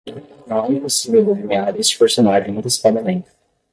Pronúnciase como (IPA)
/de.li.neˈa(ʁ)/